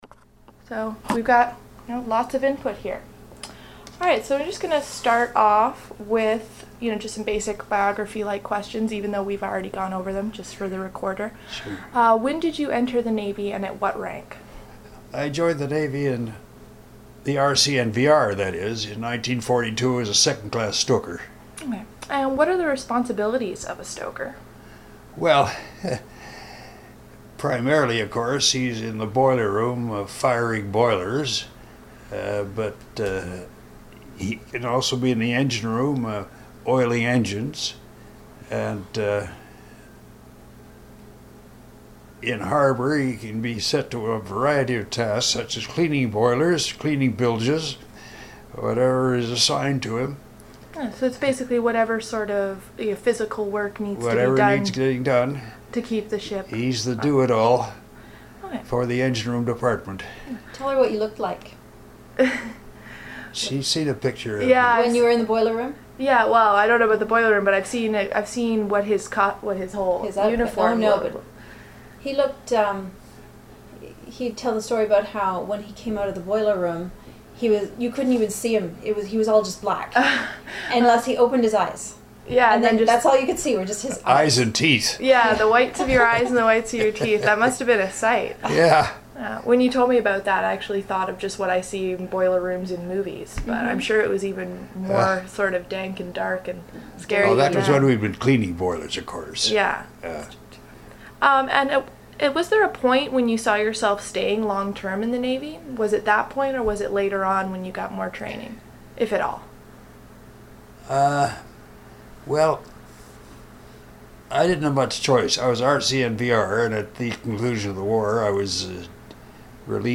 • Part of the Military Oral History Class collection of interviews.